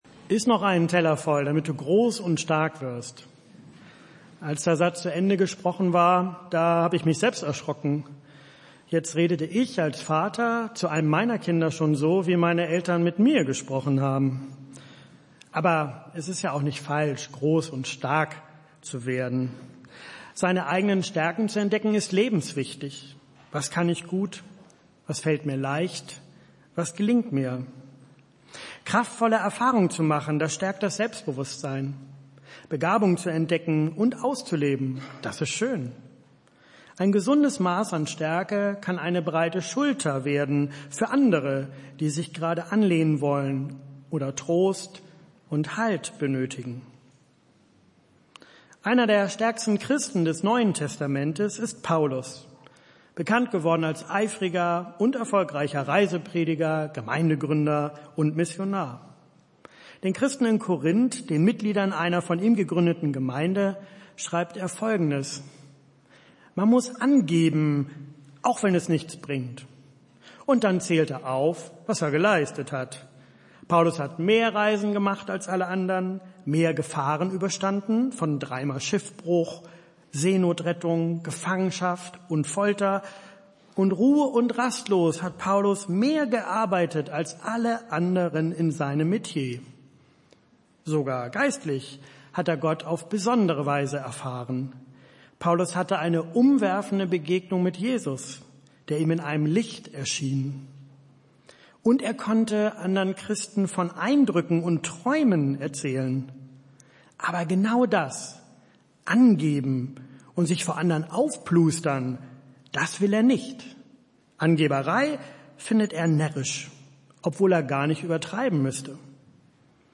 Predigttext: 2. Korinther 12, 6-10